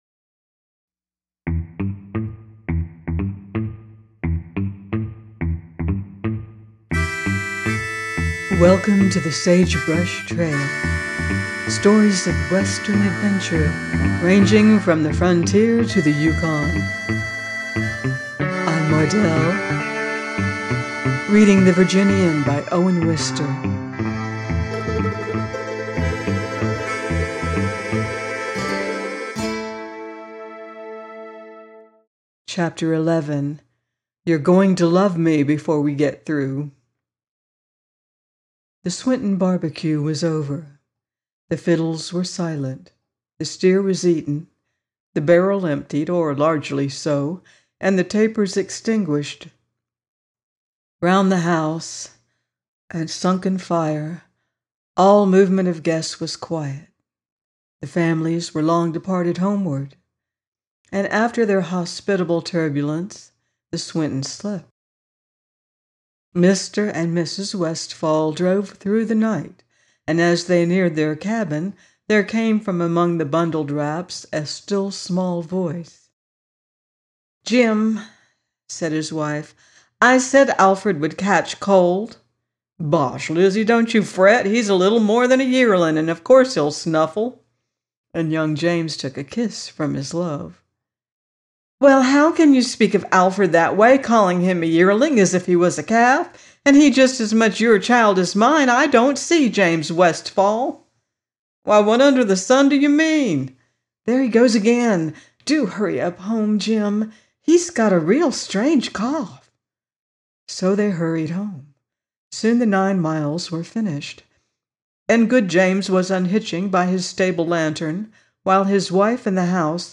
The Virginian 11 - by Owen Wister - audiobook